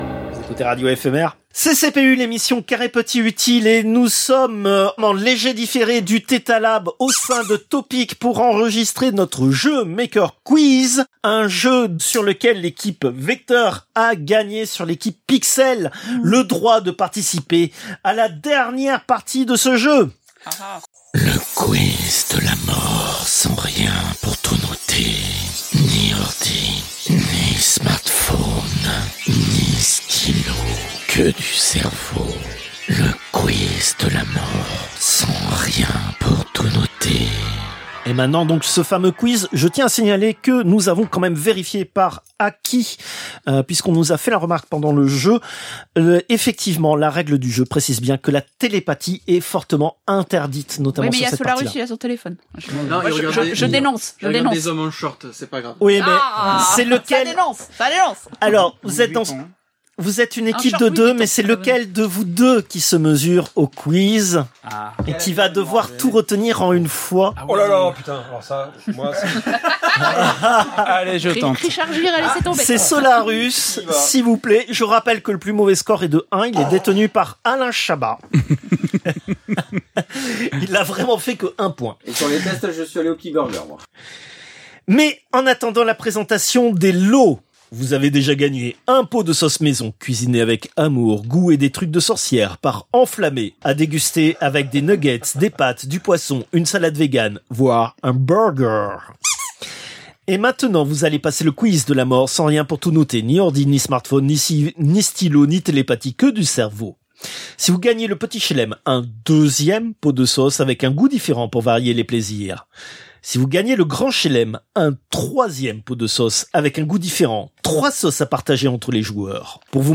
Extrait de l'émission CPU release Ex0227 : lost + found (un quart null).